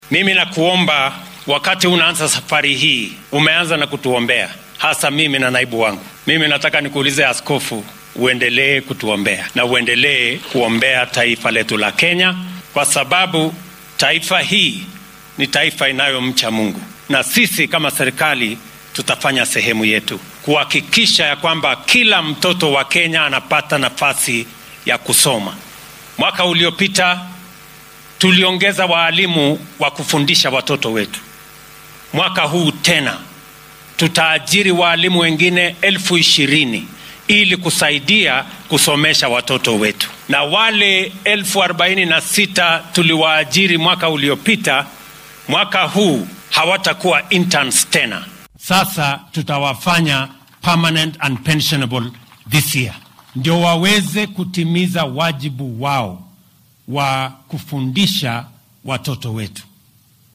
Waxaa maanta si wayn loo adkeeyay amniga magaalada Nyahururu ee ismaamulka Laikipia oo ay madaxweynaha dalka William Ruto, ku xigeenkiisa Rigathi Gachagua ay uga qayb galayeen munaasabad kaniiseed.
Waxaa  kaniisadda  gudaheeda  lagu arkayay qof  dhallinyaro ah oo isagoo sita  calanka Kenya  ku dhawaaqaya  in lagu gacan seyro  hindise sharciyeedka maaliyadda ee sanadkan.